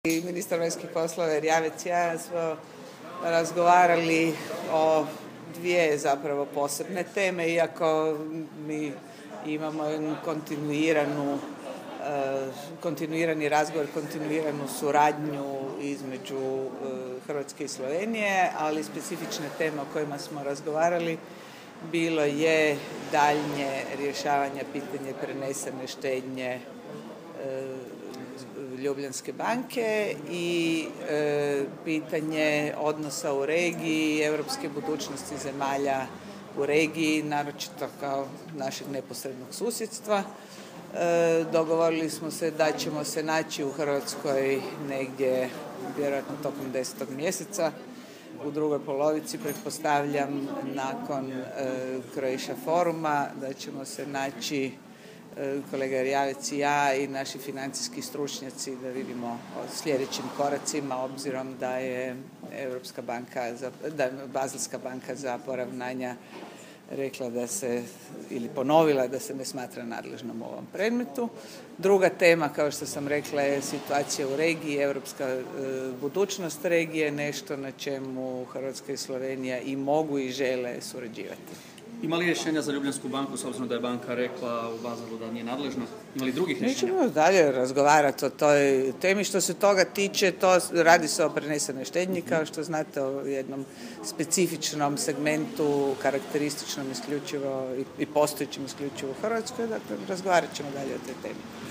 Izjava ministrice Pusić nakon sastanka sa slovenskim ministrom vanjskih poslova Karlom Erjavcem...